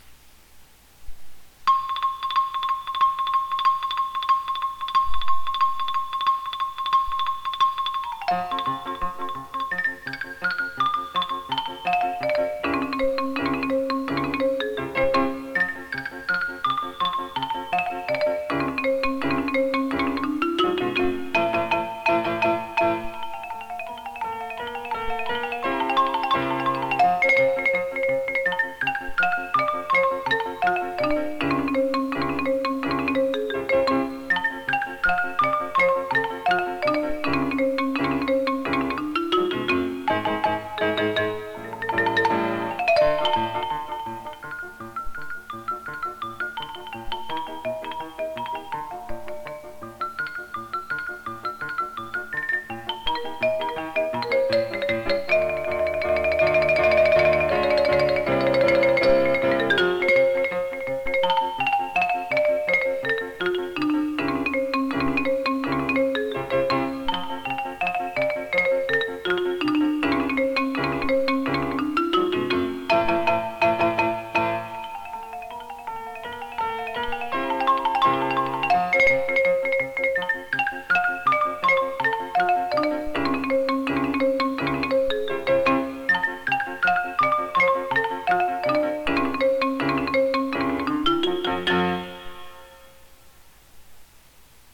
マリンバ、打楽器奏者。
ピアノ伴奏版